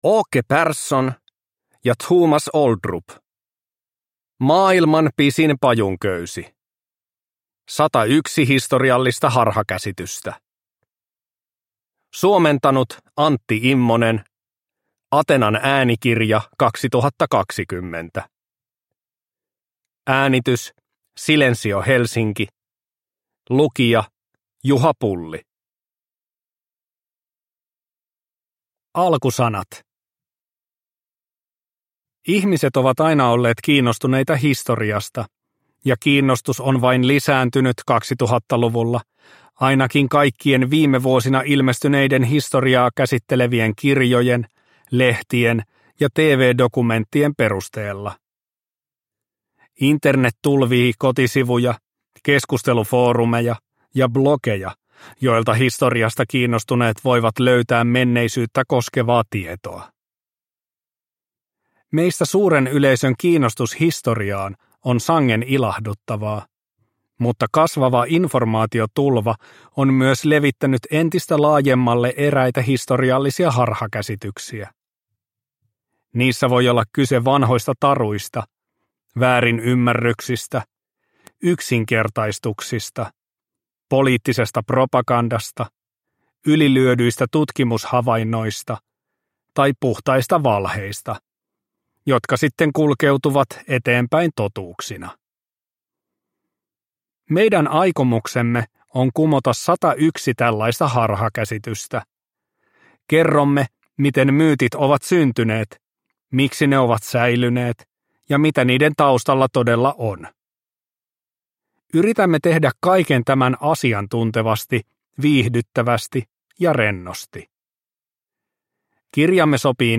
Maailman pisin pajunköysi – Ljudbok – Laddas ner